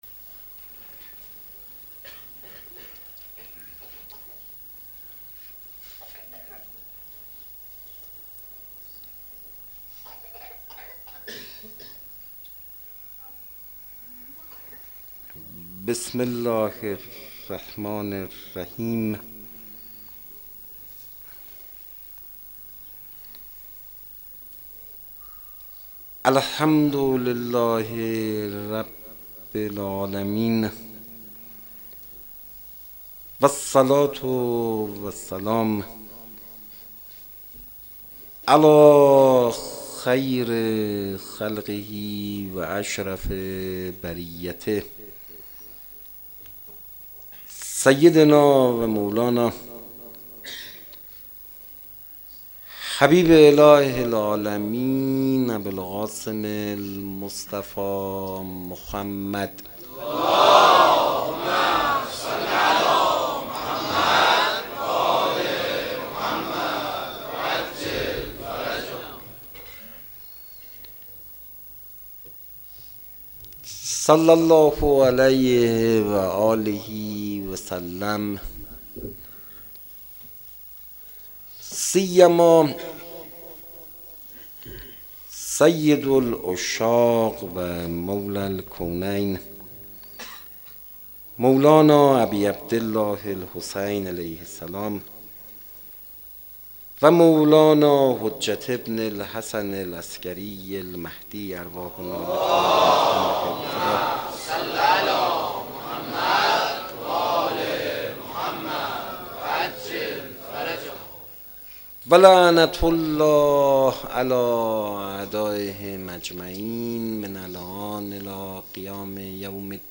مناسبت : شب دوم محرم